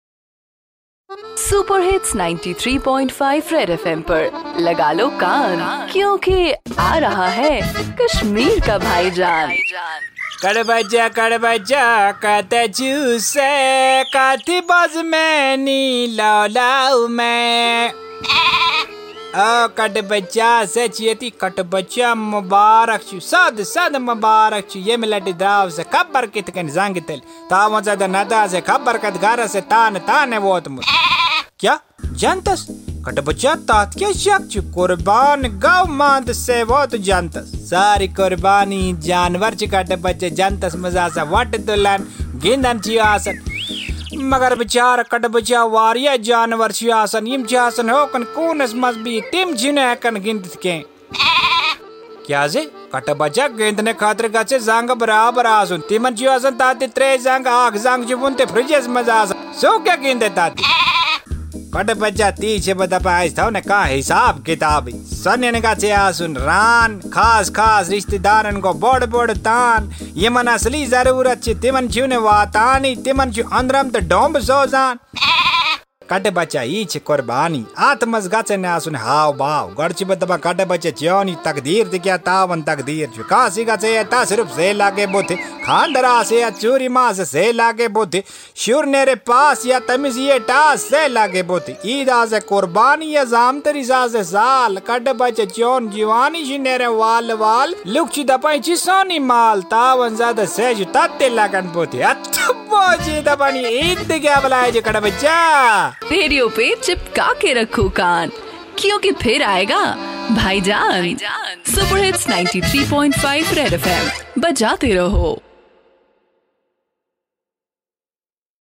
Bhaijaan the ultimate dose of comedy in Kashmir which is high on satire and humor